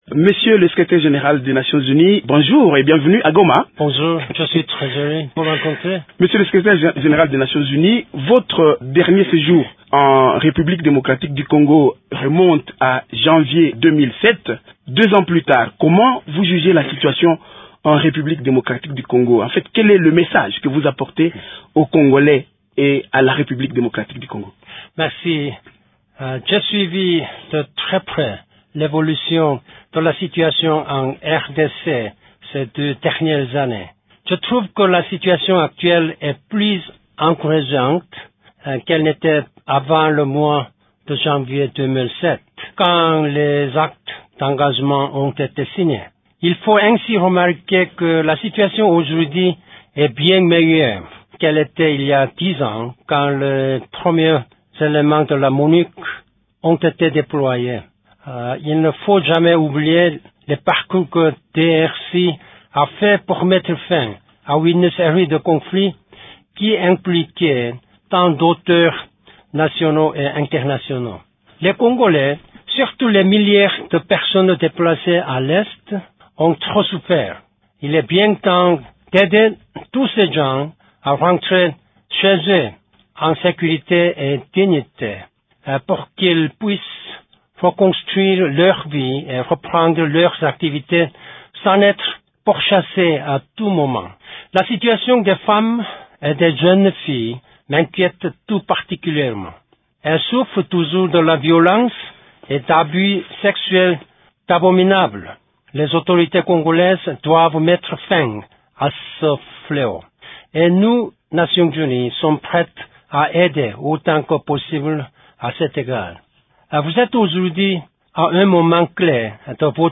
Dans une interview exclusive accordée à Radio Okapi lors de son passage à Goma, Ban Ki Moon, invité spécial de Radio Okapi, salue les avancées réalisées dans la pacification de l’Est de lé RDC.